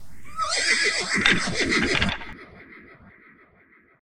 PixelPerfectionCE/assets/minecraft/sounds/mob/horse/skeleton/idle1.ogg at 508dc1a06801b7edae6f126a88cb104145f2920f